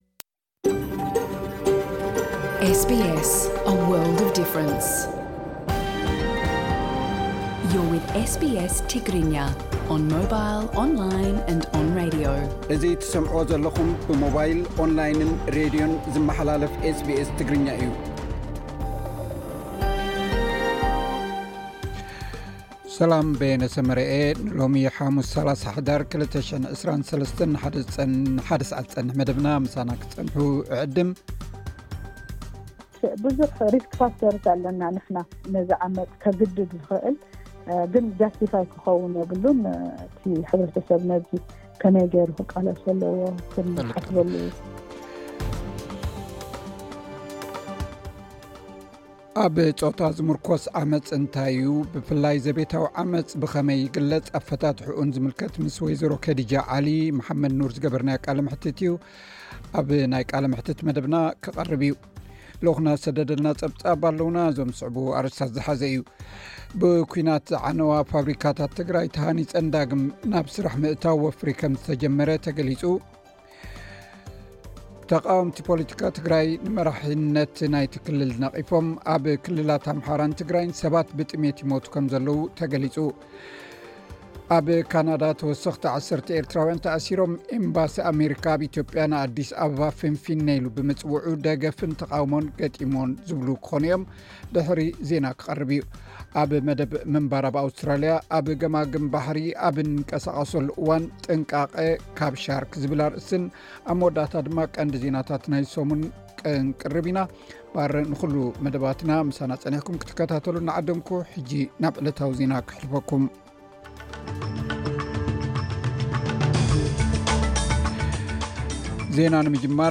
ዝገበርናዮ ቃለ መሕትት ኣለና። ልኡኽና ዝሰደደልና ጸብጻብ እዞም ዝስዕቡ ኣርእስታት ዝሓዘ እዩ። ብኲናት ዝዓነዋ ፋብሪካታት ትግራይ ተሃኒፀን ዳግም ናብ ስራሕ ምእታዉ ወፍሪ ከም ዝተጀመረ ተገሊጹ። ተቓወምቲ ፖለቲካ ትግራይ ንመሪሕነት ናይቲ ክልል ነቒፎም።